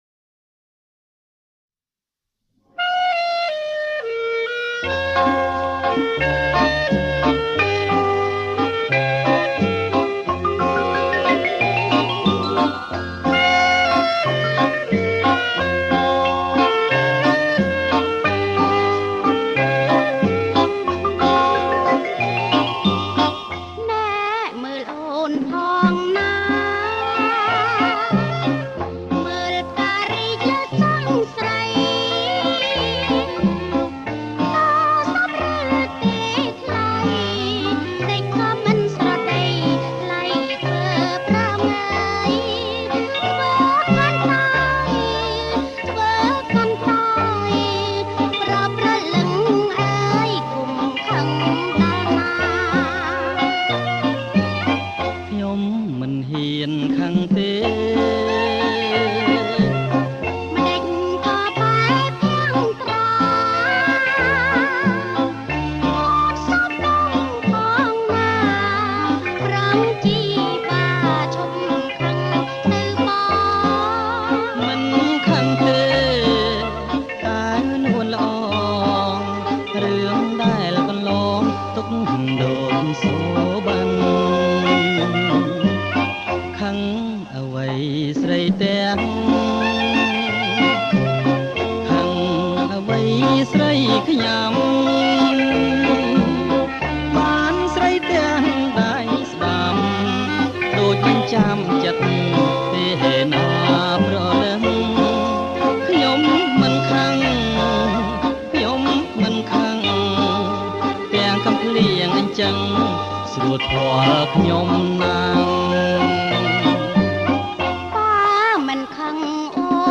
ប្រគំជាចង្វាក់ Bolero Jerk